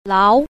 a. 勞 – láo – lao
lao.mp3